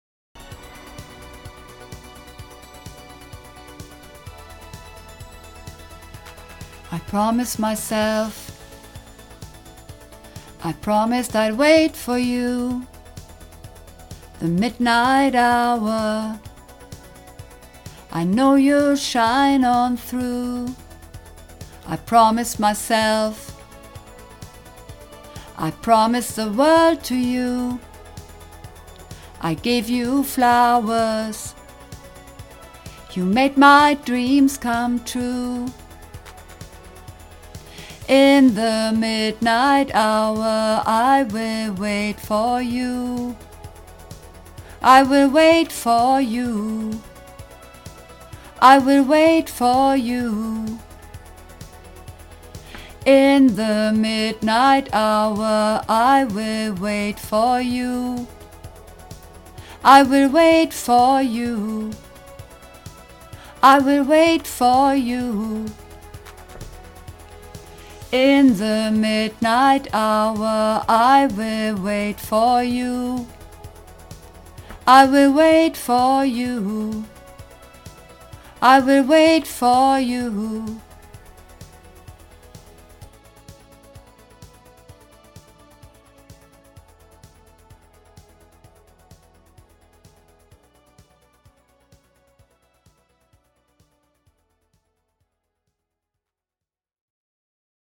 Übungsaufnahmen - I Promised Myself